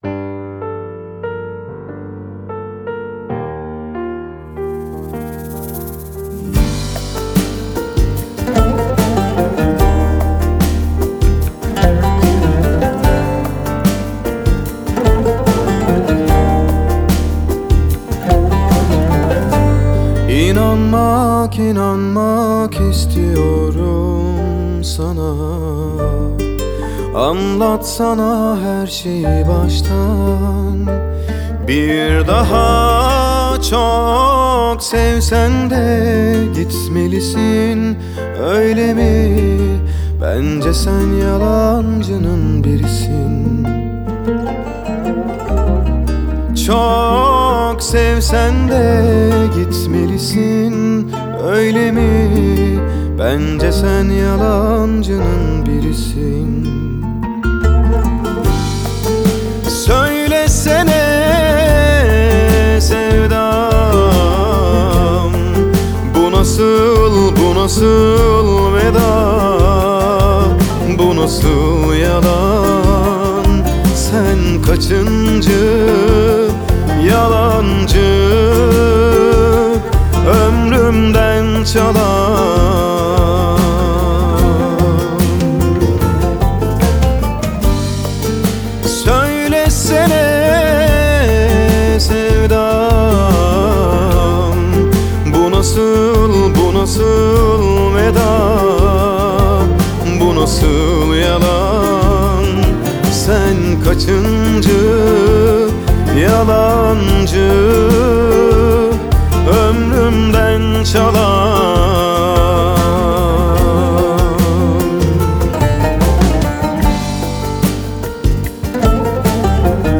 آهنگ ترکیه ای آهنگ غمگین ترکیه ای آهنگ هیت ترکیه ای
این ترانه زیبا در سال ۲۰۱۶ توسط این خواننده محبوب خوانده شده